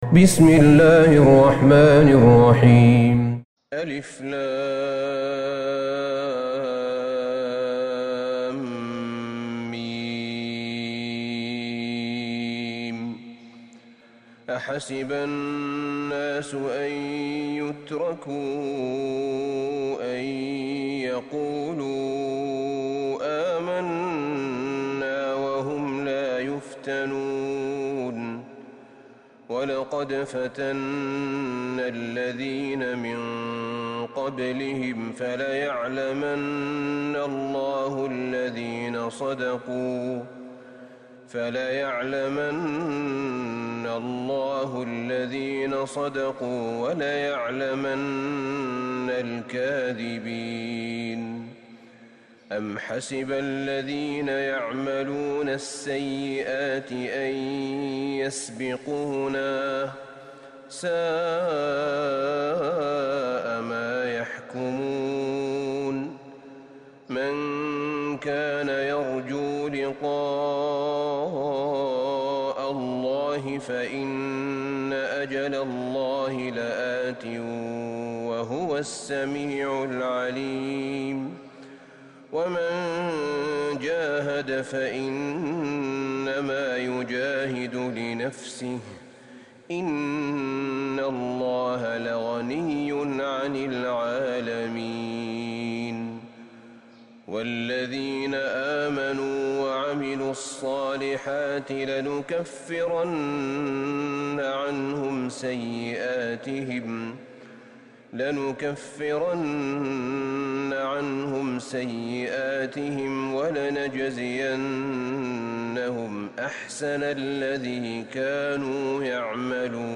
سورة العنكبوت Surat Al-Ankabut > مصحف الشيخ أحمد بن طالب بن حميد من الحرم النبوي > المصحف - تلاوات الحرمين